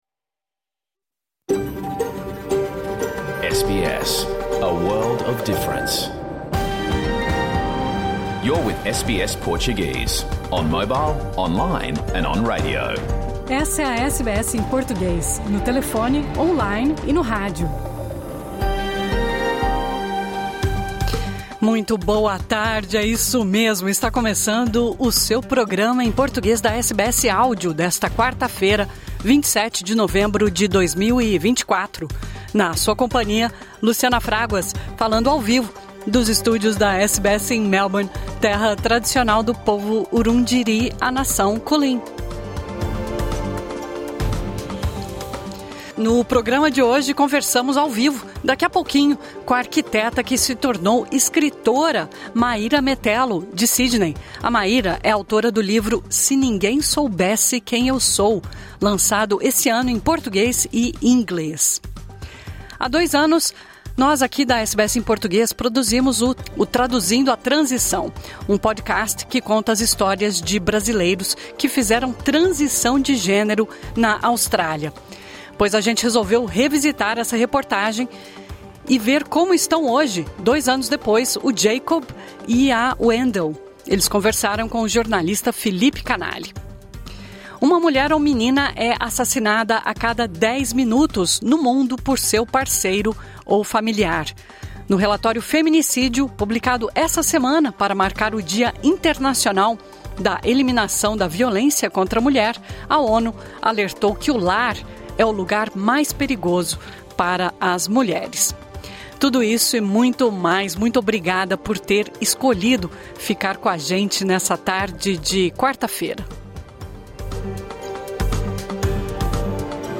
Programa que foi ao ar ao vivo hoje pela SBS Áudio.